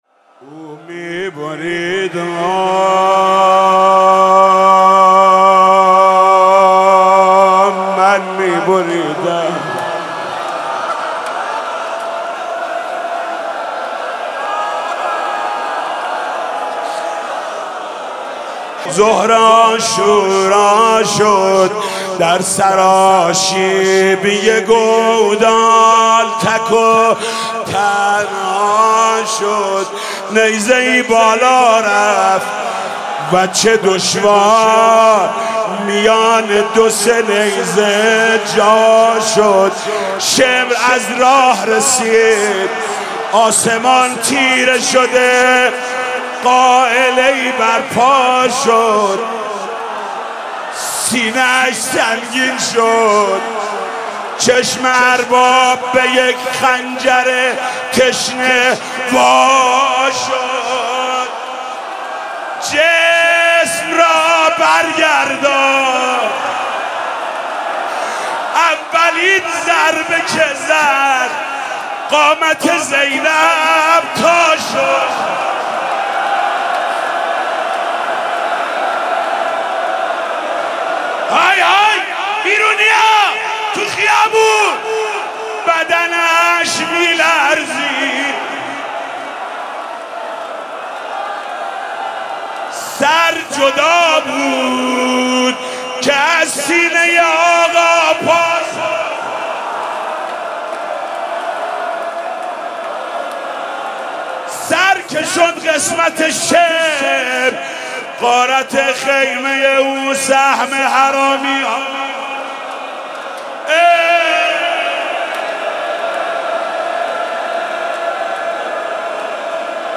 مداحی شام غریبان حاج محمود کریمی